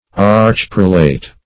Search Result for " archprelate" : The Collaborative International Dictionary of English v.0.48: Archprelate \Arch`prel"ate\, n. [Pref. arch- + prelate.] An archbishop or other chief prelate.
archprelate.mp3